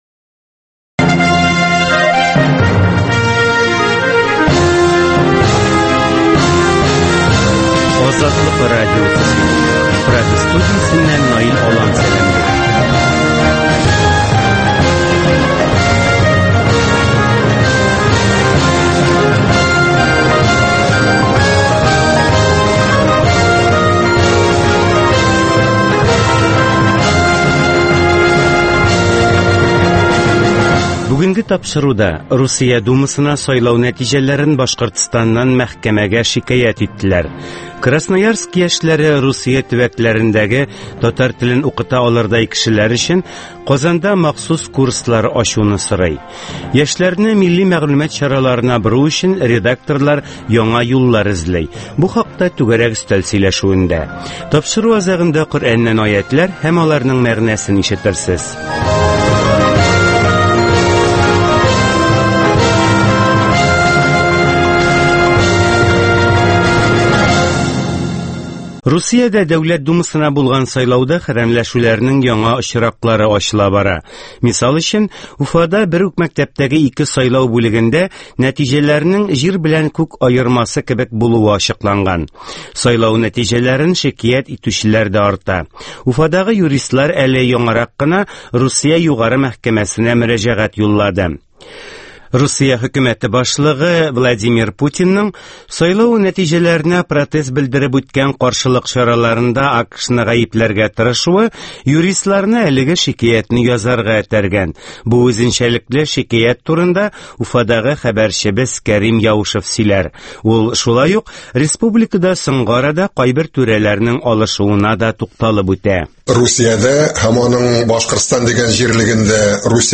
Азатлык узган атнага күз сала - Башкортстаннан атналык күзәтү - Татар дөньясы - Түгәрәк өстәл сөйләшүе - Коръәннән аятләр һәм аларның мәгънәсе